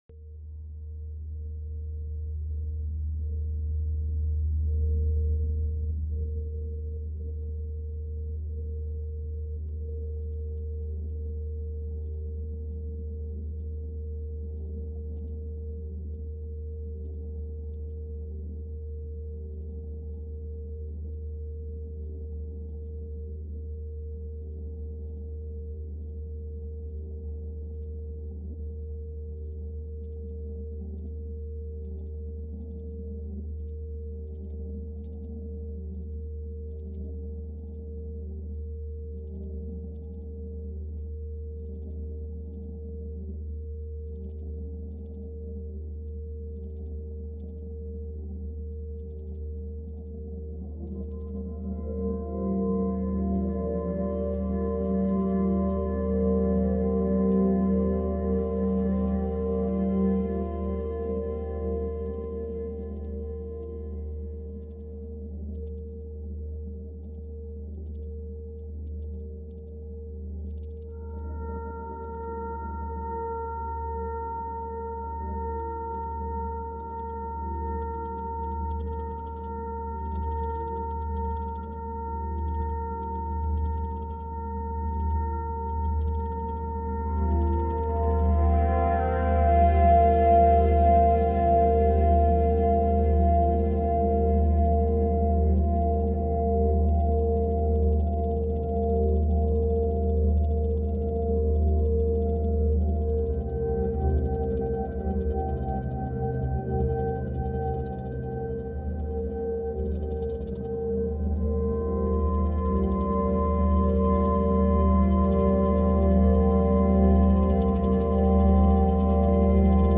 深い闇の中でも、 ひとつの温もりが心を照らす。 今回は 145Hz の音とともに、 sound effects free download